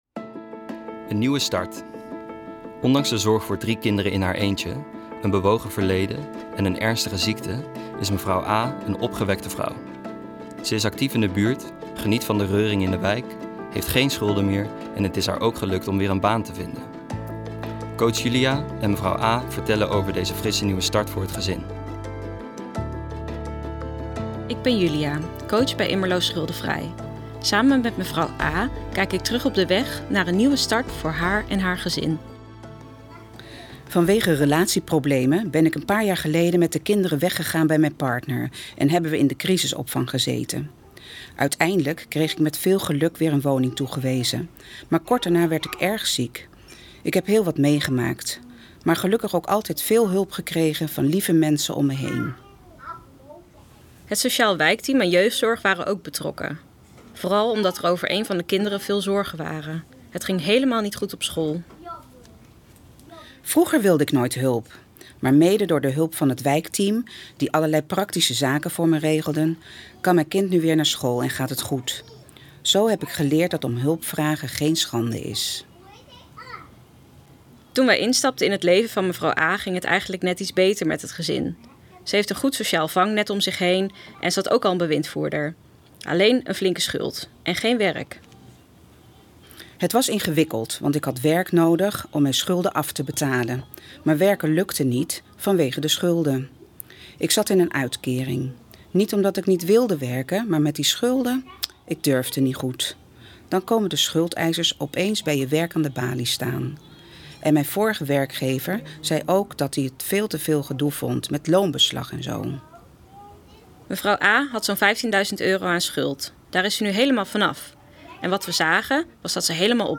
De verhalen worden verteld door stemacteurs.